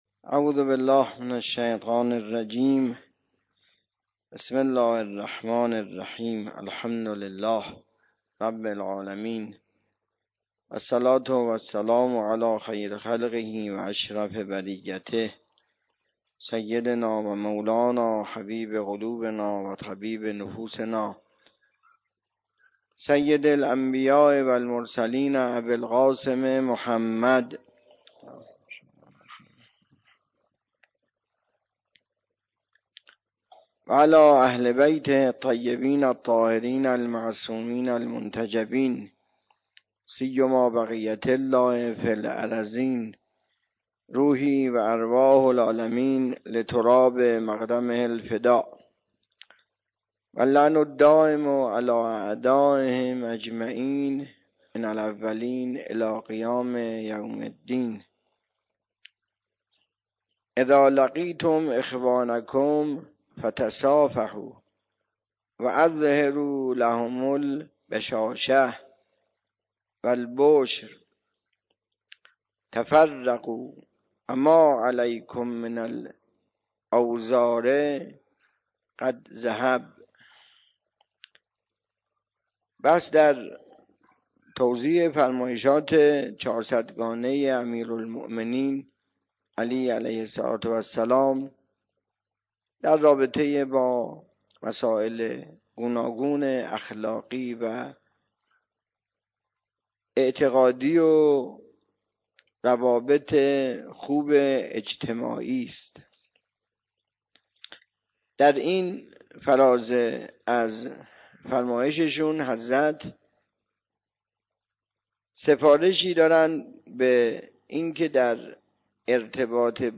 سخنرانی
درس اخلاق